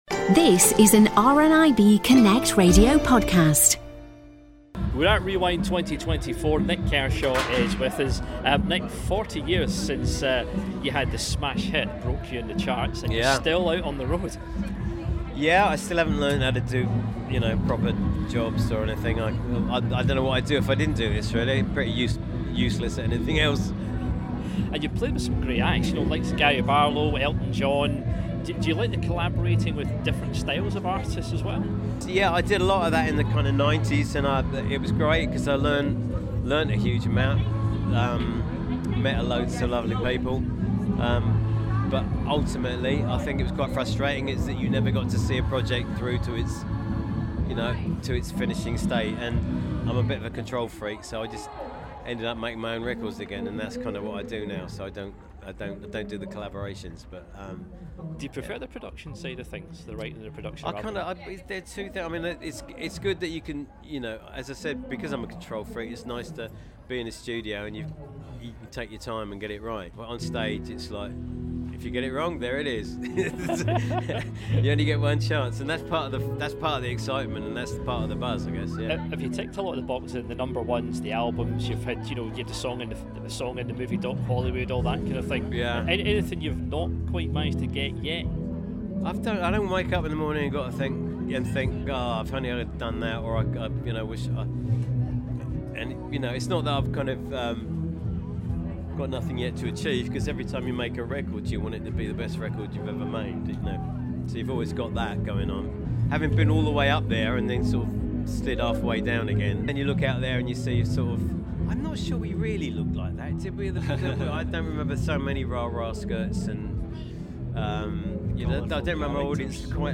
Rewind Scotland 2024 returned to the spectacular grounds of Scone Palace in Perth on Friday 19th till Sunday 21st of July to celebrate music icons of the 80s and beyond.